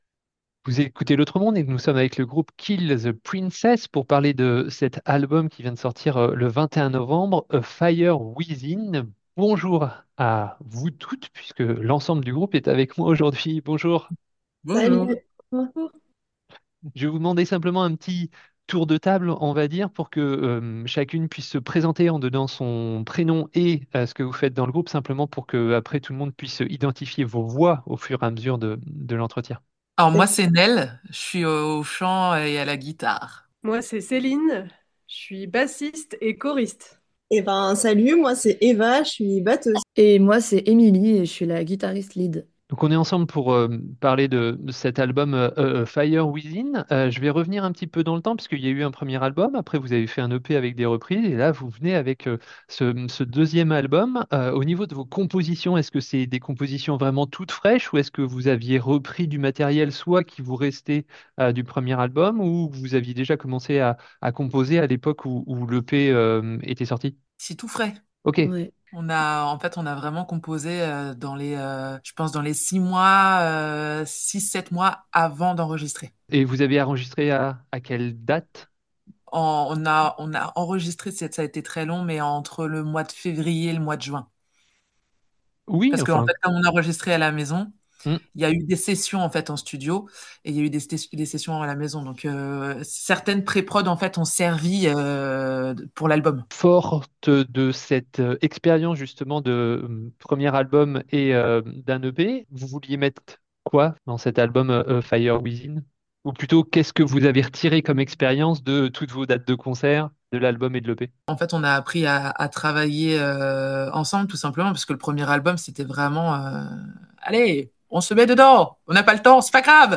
interview du groupe KILL THE PRINCESS pour la sortie de leur deuxième album A Fire Within (lien vers la chronique de l'album)